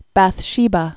(băth-shēbə, băthshə-)